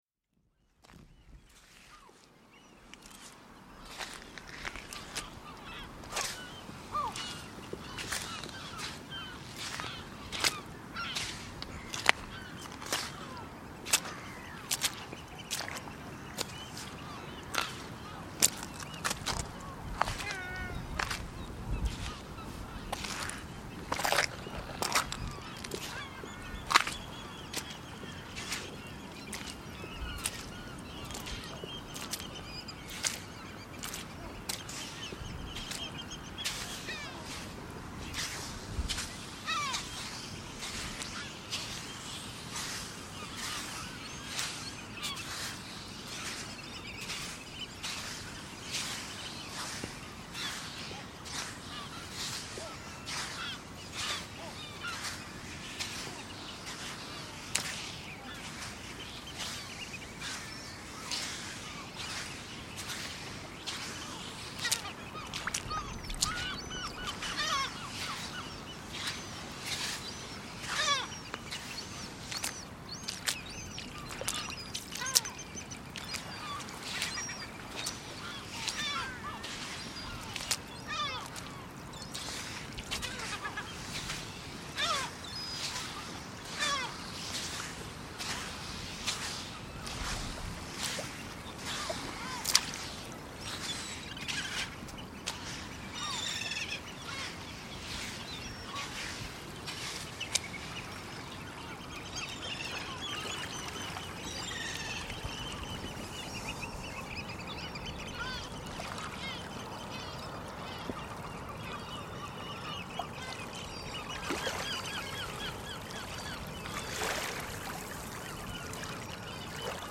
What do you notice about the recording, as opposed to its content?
Early morning at the beach in Portobello, Edinburgh, Scotland.